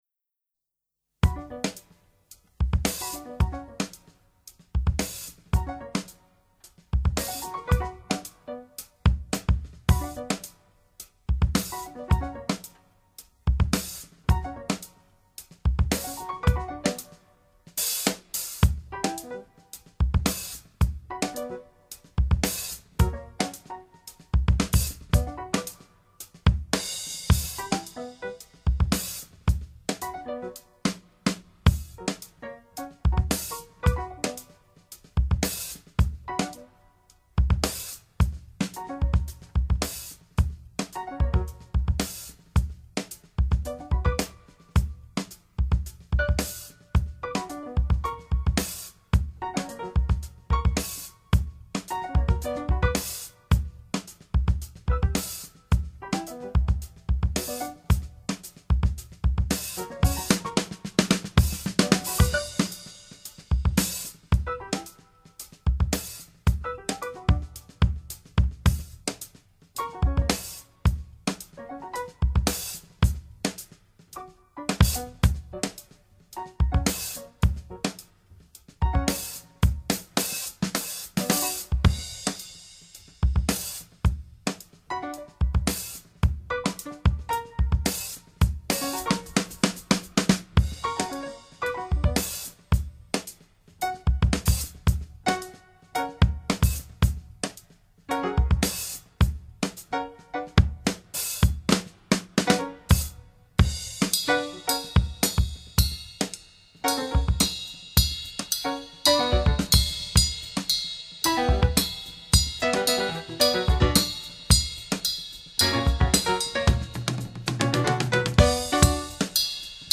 ITW du groupe Twofer programmé sur le Off du Festival Jazz à Sète, réalisée le Lundi 12 Juin 2023 @ Radio Muge Studio.